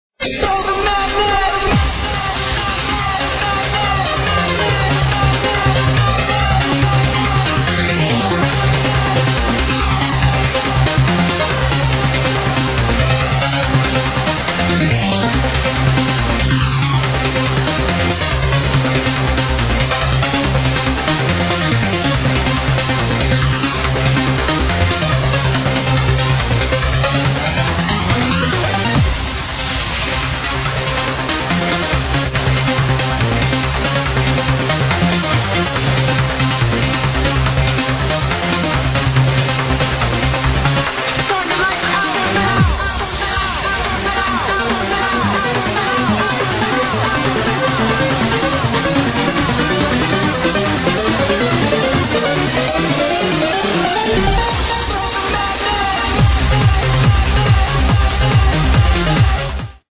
contains a sample that sounds like 'Throw the madness'